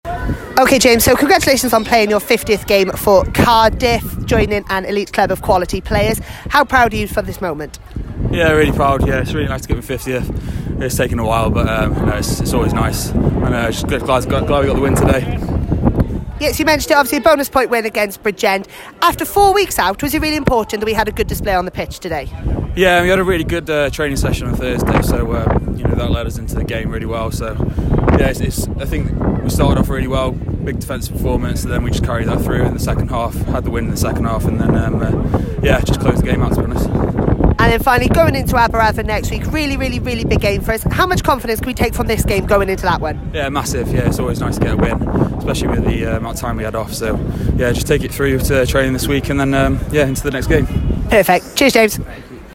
Post-Match Interviews.